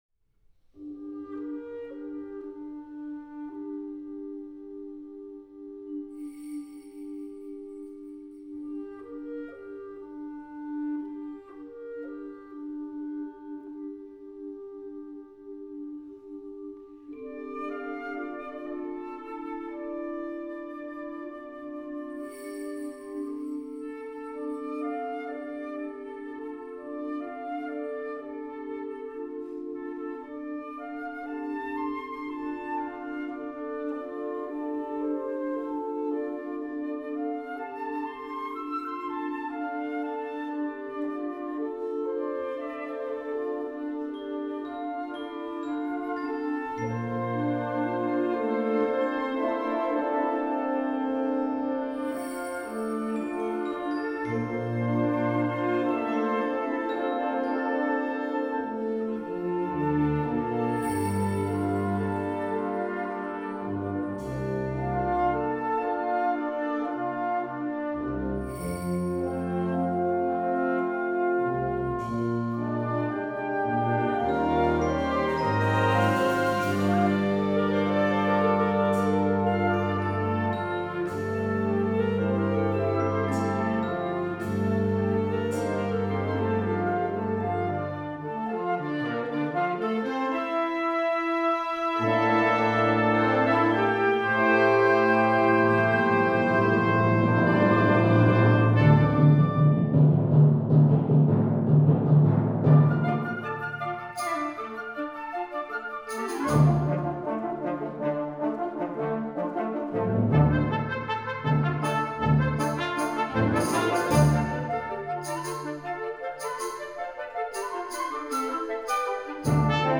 Partitions pour ensemble flexible, 7-voix + percussion.